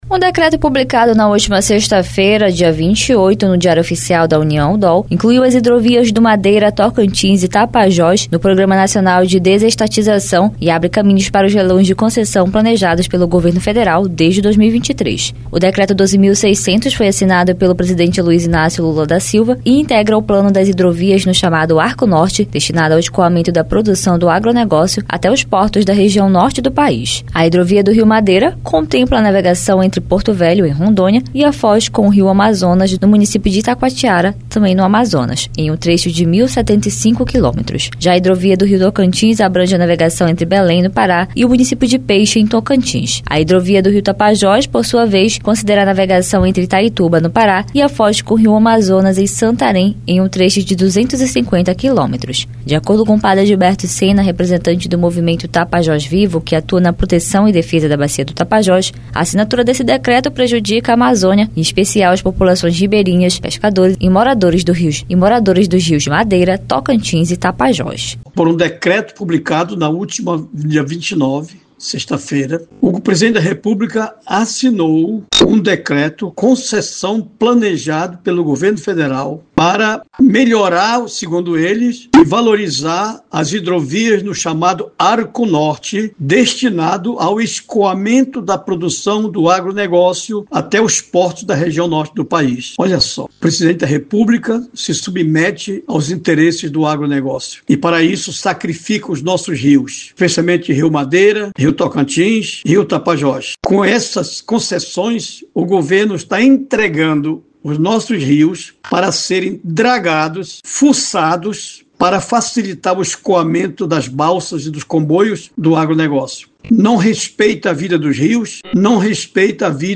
A reportagem